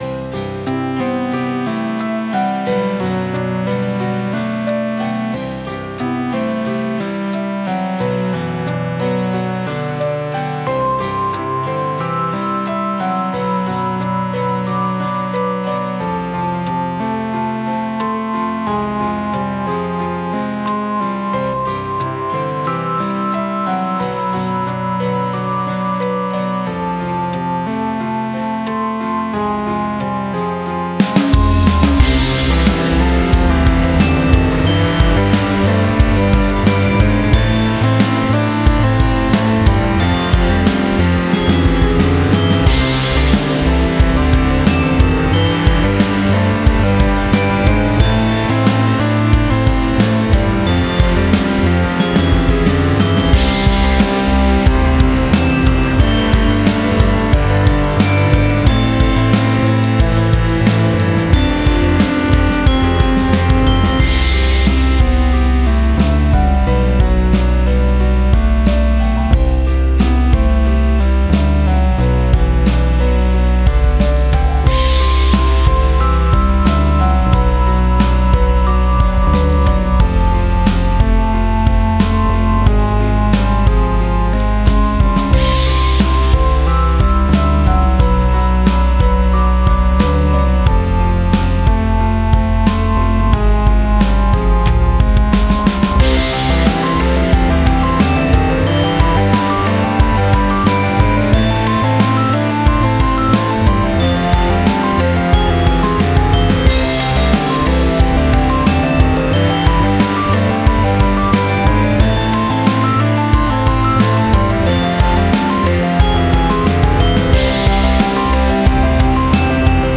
Style Style Pop, Rock
Mood Mood Bright, Uplifting
BPM BPM 90